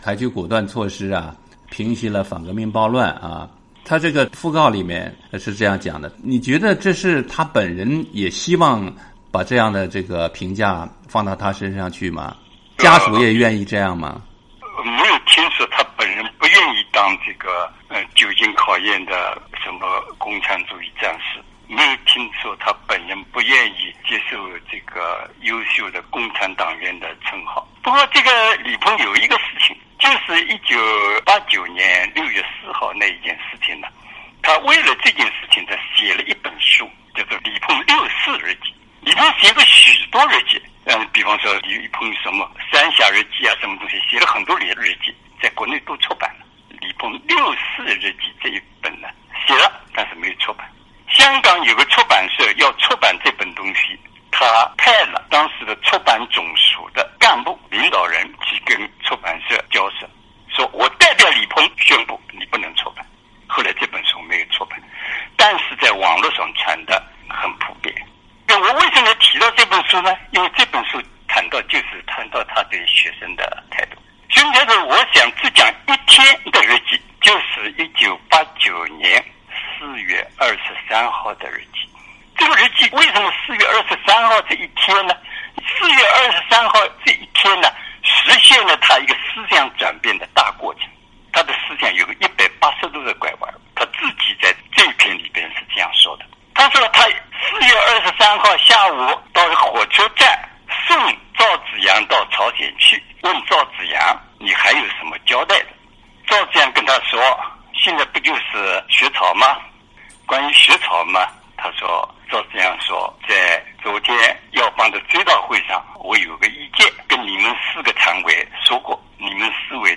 原赵紫阳政治秘书鲍彤最近在接受美国之音专访时表示，李鹏在六四镇压决策中的作用被高估。鲍彤指出，李鹏当时的日记隐约透露，时任总书记赵紫阳出访朝鲜期间，李鹏可能在时任国家主席杨尚昆陪同下与邓小平密谈，随后态度急剧转变，由同意和平处理学潮转为支持武力镇压。
（根据采访录音整理，受访者观点不代表美国之音）